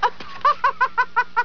Laughs... More laughs...